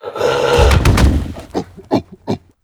c_goril_bat1.wav